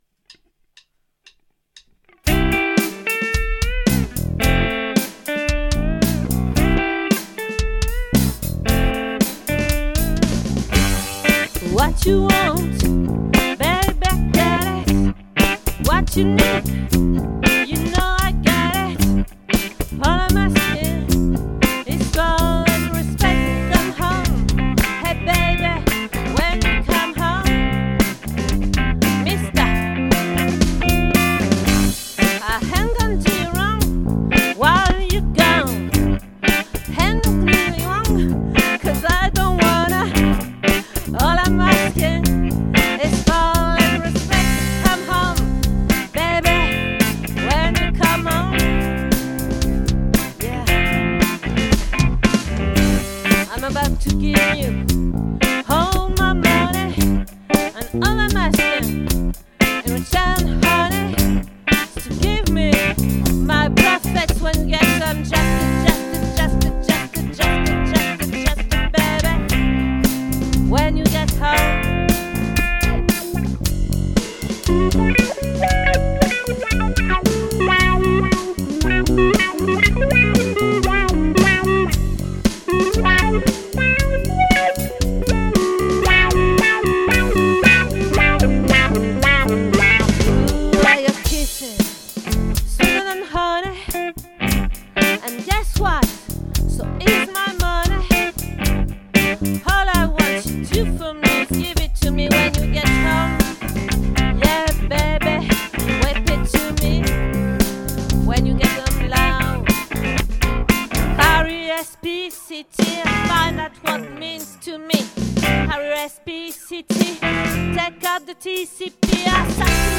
🏠 Accueil Repetitions Records_2022_03_16